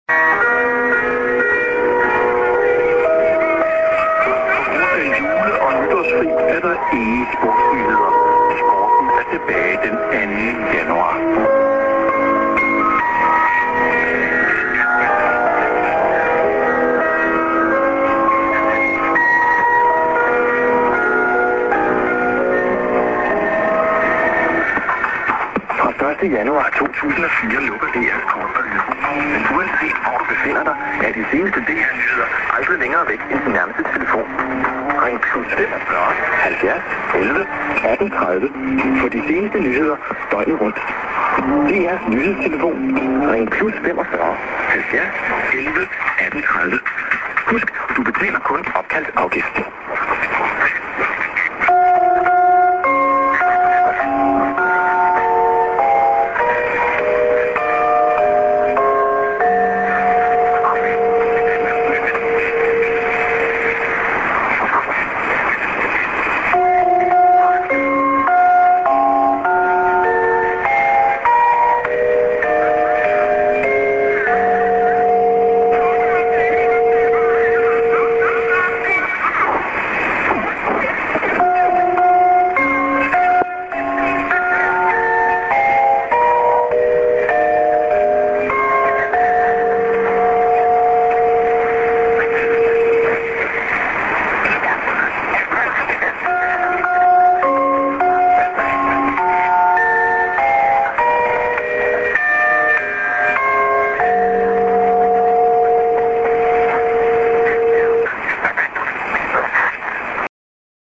music->ANN(man)->IS-> s/off